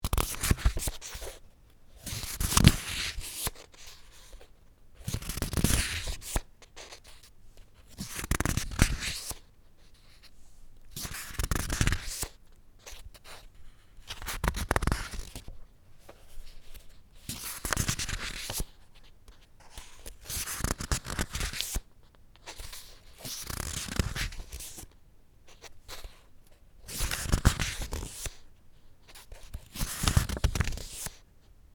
Download Book Pages sound effect for free.